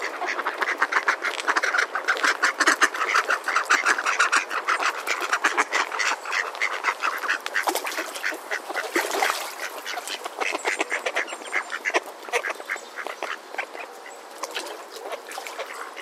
水中的鸭子
描述：大约十几只鸭子在一个小池塘边嘎嘎叫，其中几只鸭子跳进水里，溅起了水花。用Zoom H2录制。低于350赫兹的频率被滚去，以帮助消除环境中的城市隆隆声
Tag: 池塘 嘎嘎 嘎嘎 飞溅